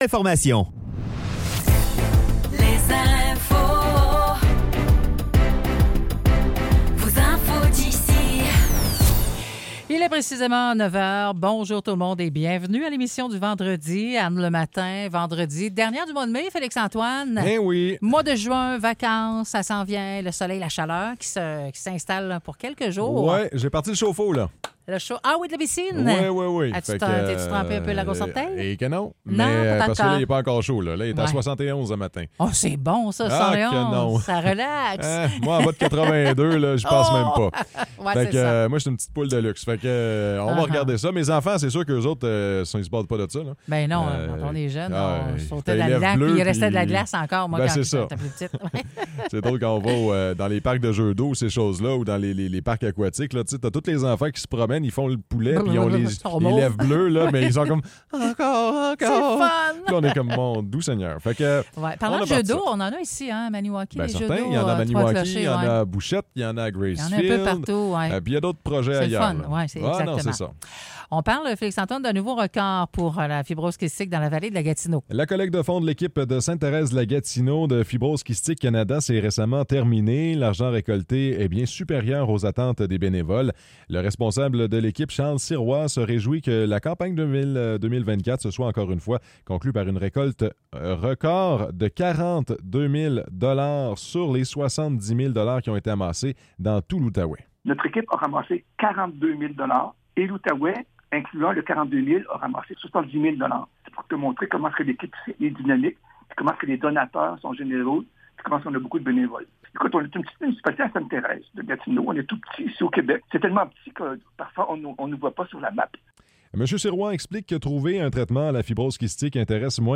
Nouvelles locales - 31 mai 2024 - 9 h